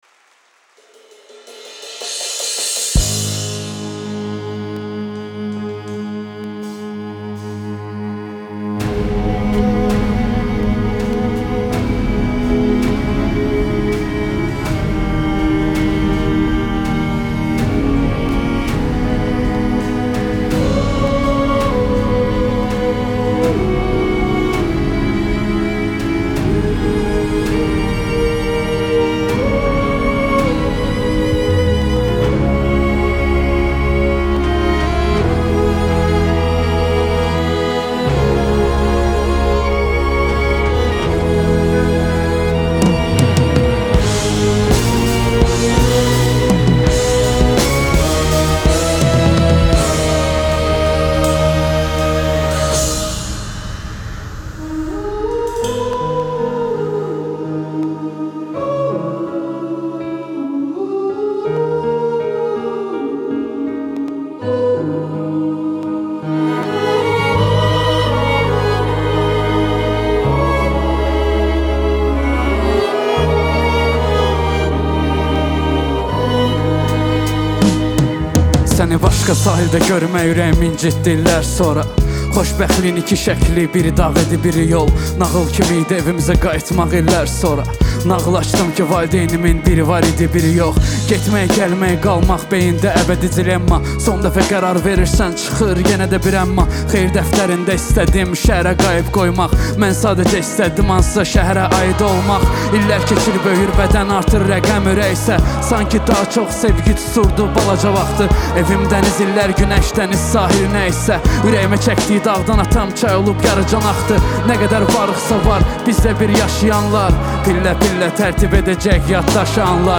Xor Kapellasi İle